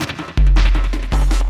53 LOOP 06-L.wav